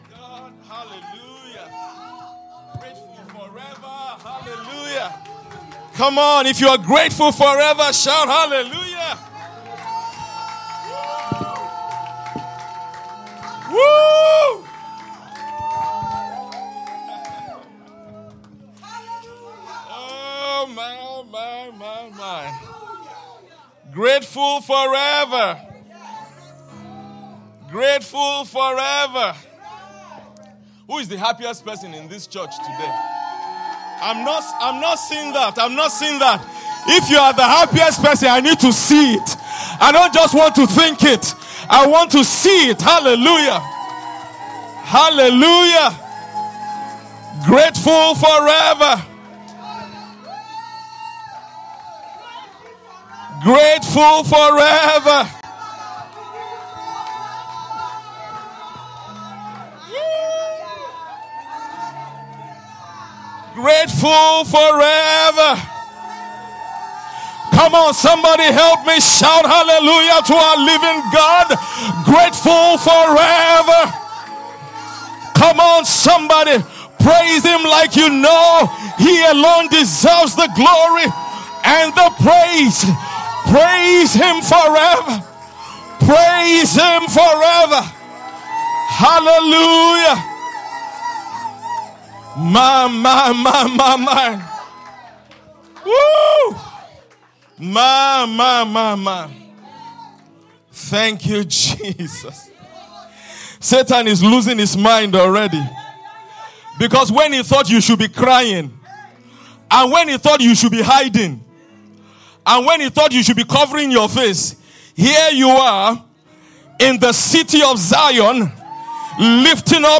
ALICC 23rd Anniversary Celebration